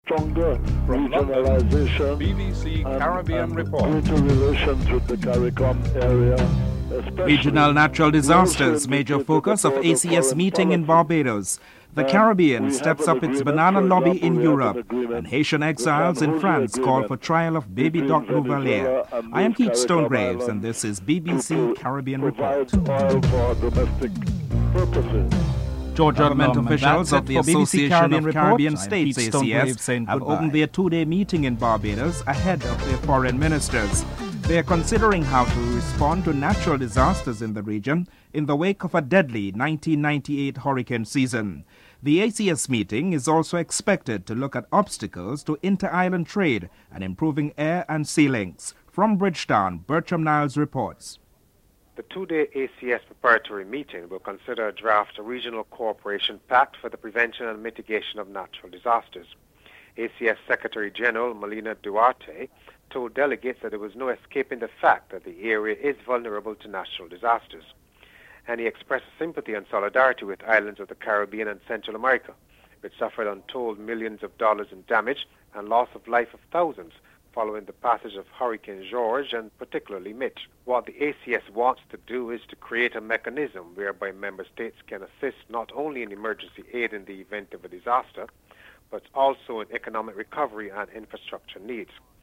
Prime Minister Kenny Anthony is interviewed (02:26-06:31)
4. The Caribbean steps up its banana industry. Prime Minister Edison James is interviewed (06:32-09:38)